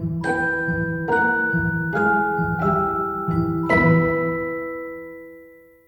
Âm thanh Tâm trạng bất ổn và Cái kết xuất hiện
Thể loại: Hiệu ứng âm thanh
Description: Âm thanh này mô phỏng cảm giác bối rối, lo lắng với những nốt nhạc lạc điệu, nhịp độ không ổn định. Thường dùng trong video để biểu hiện tâm trạng căng thẳng rồi có kết thúc vui nhộn, tạo điểm nhấn hài hước cho nội dung.